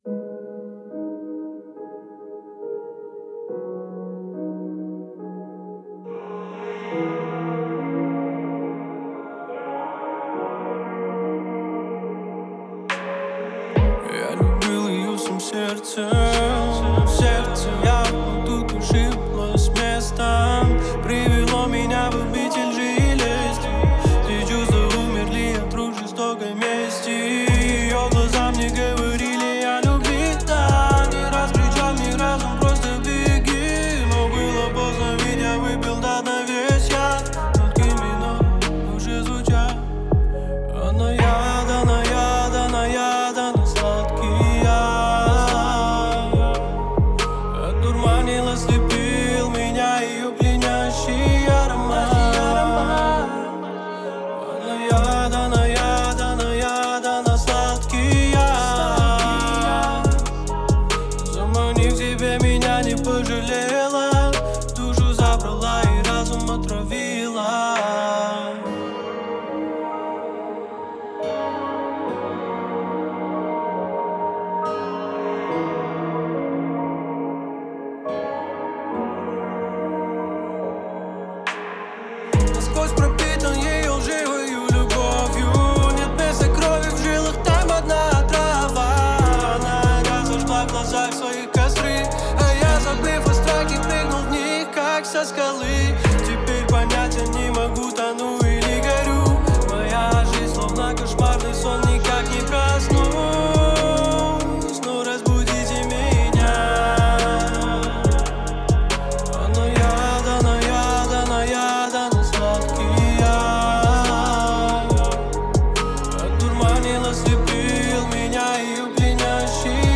выполненная в жанре поп с элементами электроники.